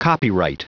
Prononciation audio / Fichier audio de COPYRIGHT en anglais
Prononciation du mot : copyright